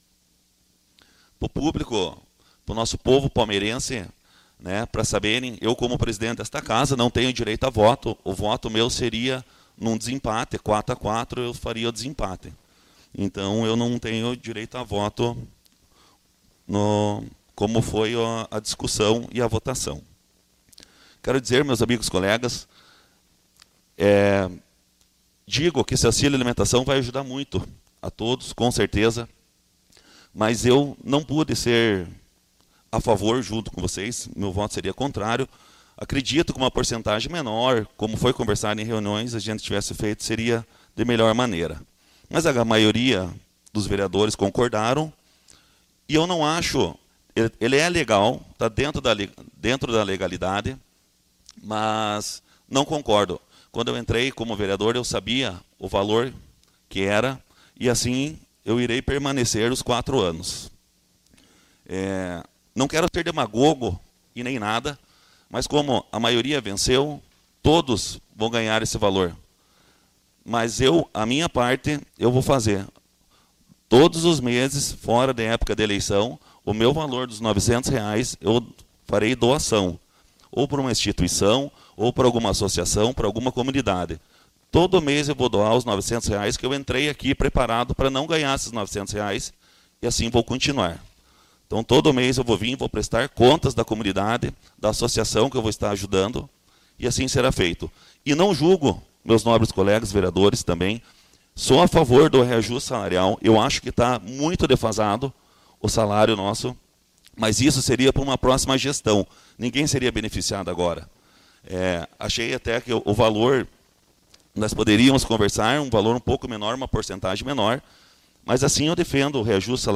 Ao final da sessão extraordinária realizada nesta quarta-feira (17), o presidente da Câmara Municipal de Palmeira, vereador Diego Zanetti, utilizou seu discurso de encerramento para se manifestar sobre o projeto que aprovou o auxílio-alimentação aos vereadores do município.
Ouça, na íntegra, a fala final do presidente do Legislativo Diego Zanetti ao término da sessão extraordinária: